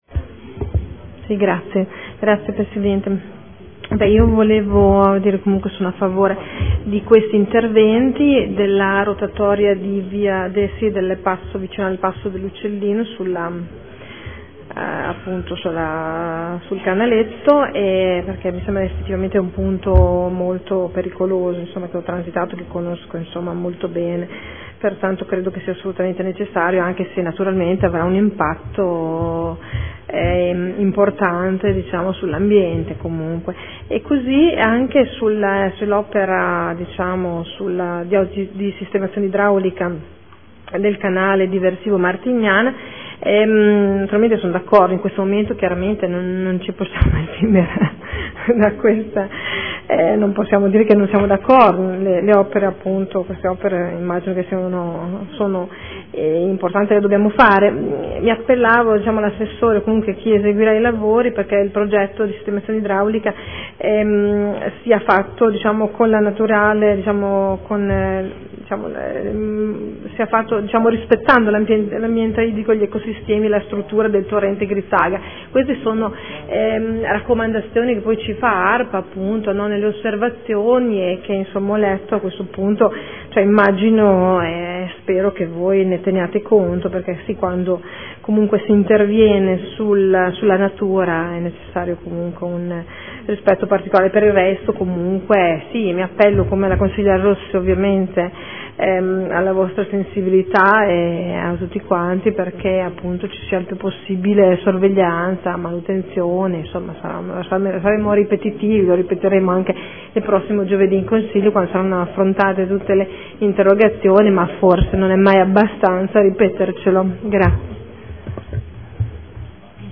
Seduta del 23/01/2014 Variante al Piano Operativo Comunale (POC) – Interventi di messa in sicurezza idraulica della città di Modena – Canale diversivo Martiniana – Nuova rotatoria sulla S.S. n. 12 “Abetone – Brennero” in località Passo dell’Uccellino